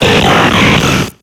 Cri de Registeel dans Pokémon X et Y.